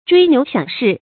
椎牛飨士 chuí niú xiǎng shì
椎牛飨士发音